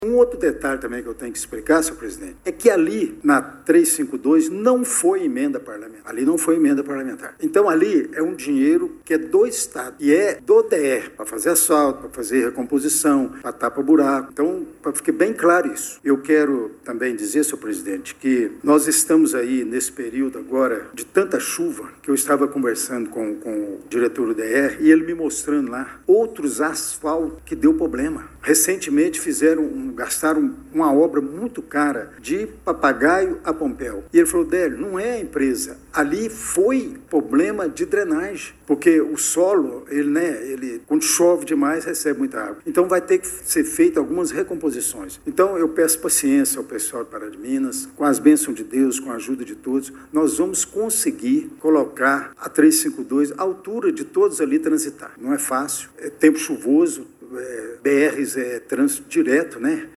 Durante reunião da Câmara Municipal de Pará de Minas, realizada ontem, 31 de março, o vereador Délio Alves Ferreira (PL) voltou a abordar os problemas no recapeamento da BR-352, executado no fim de 2025 com investimento público significativo.